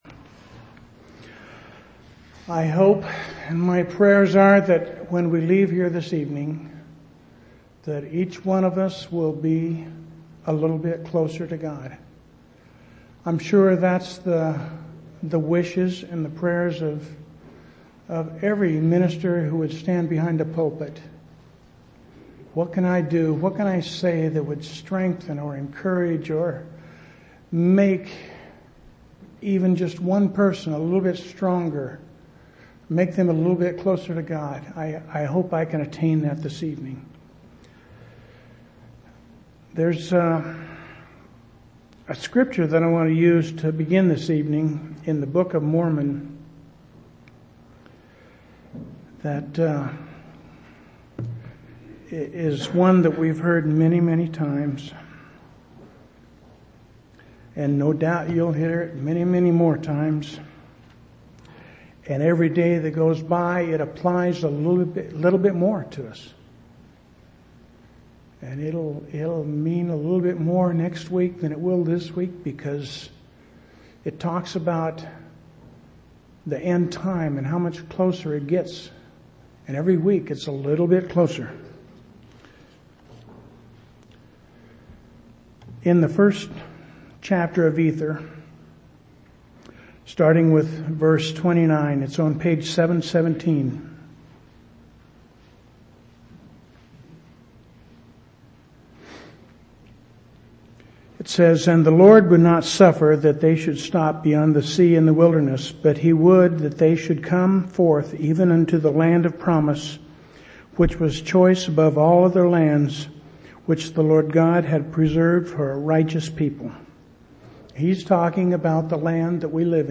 8/24/2003 Location: Temple Lot Local Event